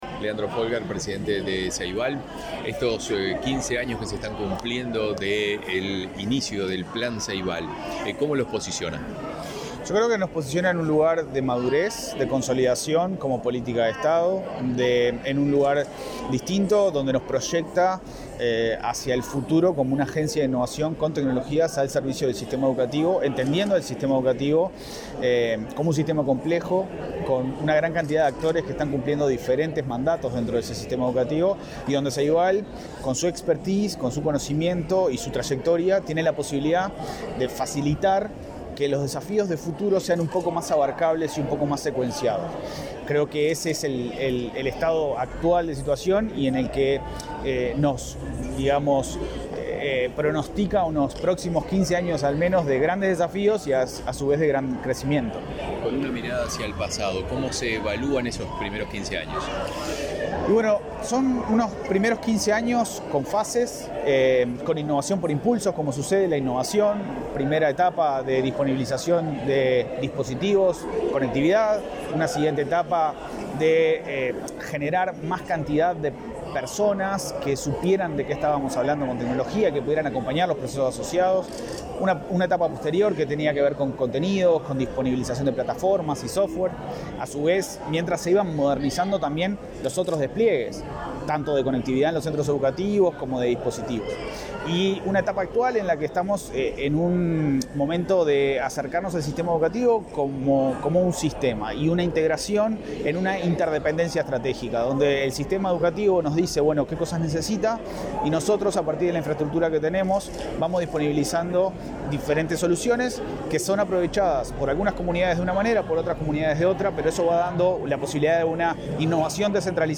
Declaraciones a la prensa del presidente de Ceibal, Leandro Folgar
Ceibal festejó 15 años desde su creación, este 30 de mayo. Su presidente Leandro Folgar, antes del evento, efectuó declaraciones a la prensa.